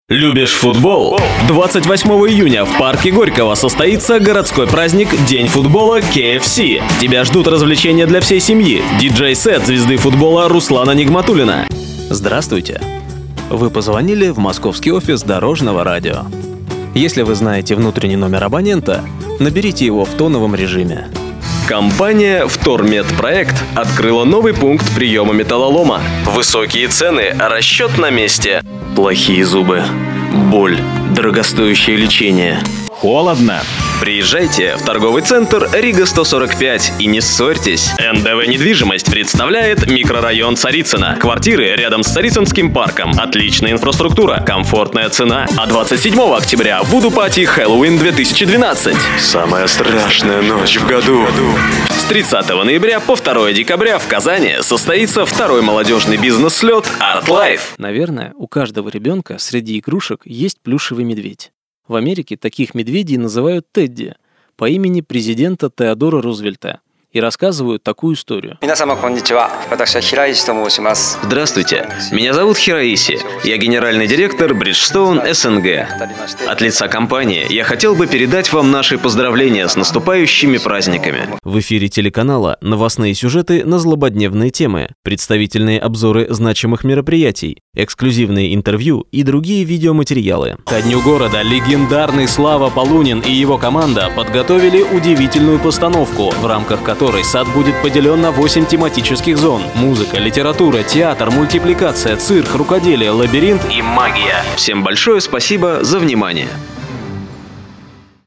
Фрагменты с дикторским голосом Категория: Аудио/видео монтаж
Подборка фрагментов с моим дикторским голосом.